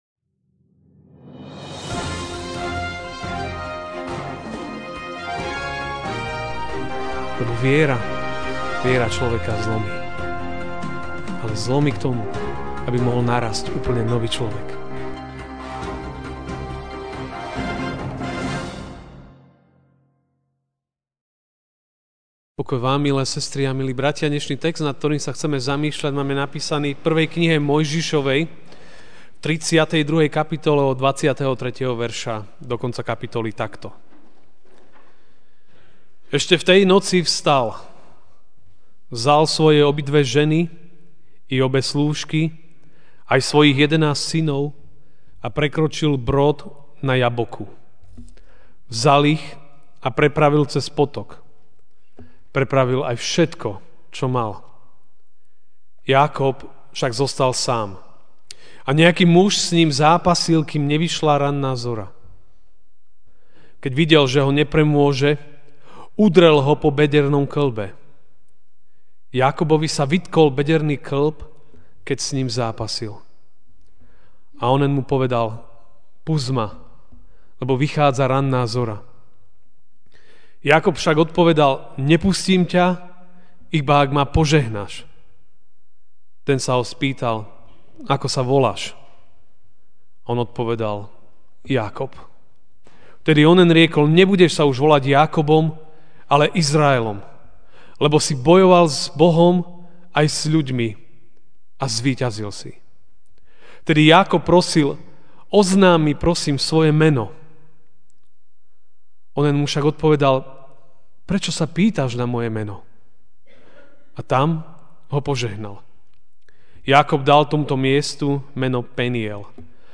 Ranná kázeň: Nevzdávaj sa v zápase, lebo Boh ťa chce požehnať.